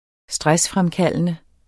Udtale [ -ˌfʁamˌkalˀənə ]